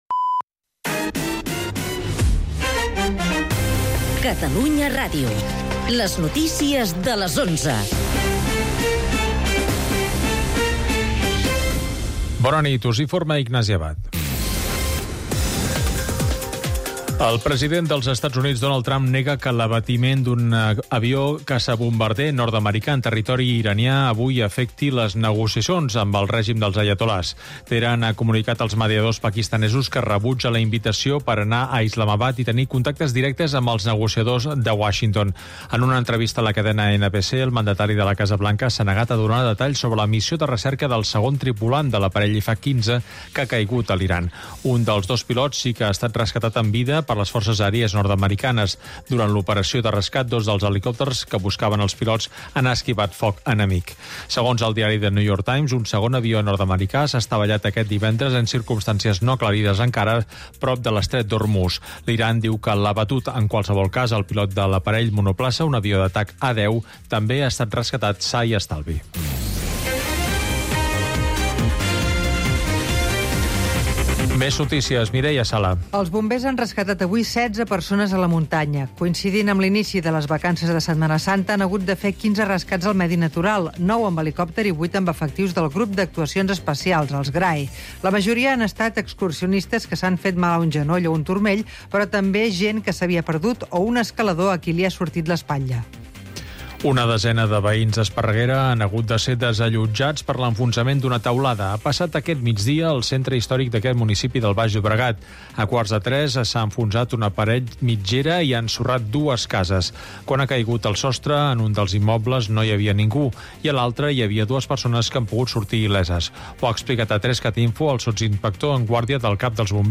informatiu diari